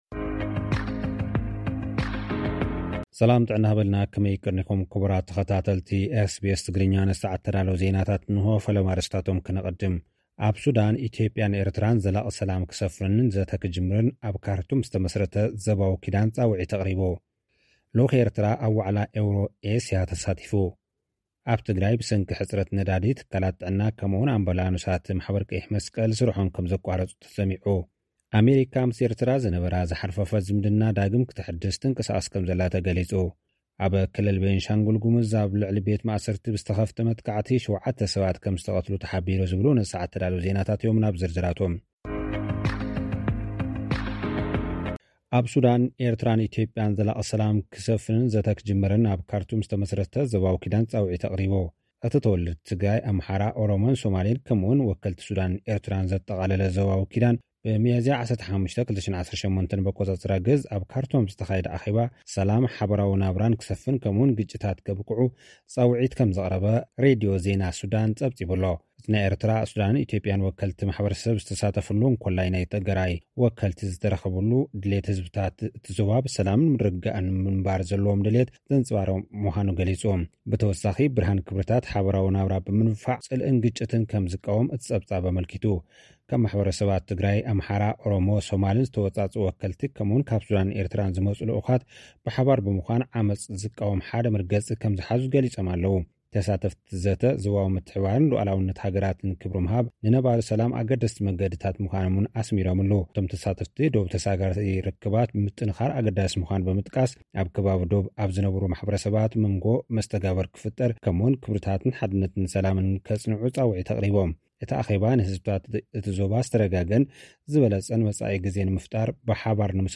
SBS Tigrinya Newsflash